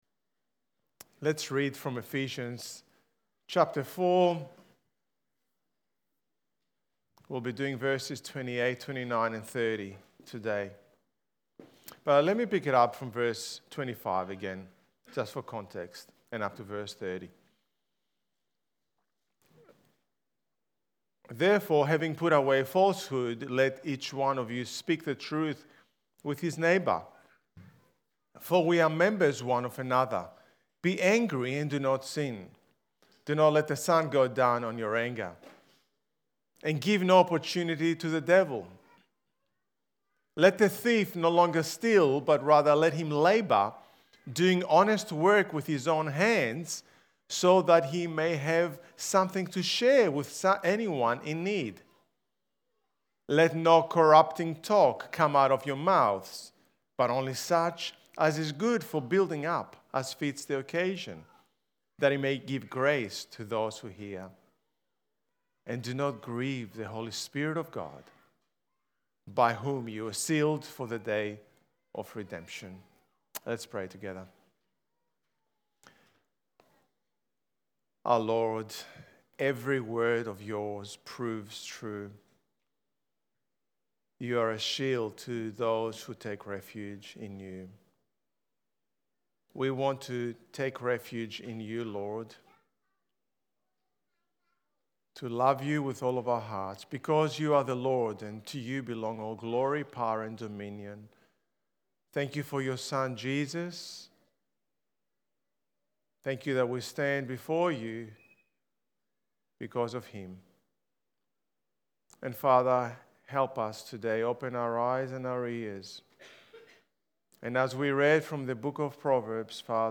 Ephesians Sermon Series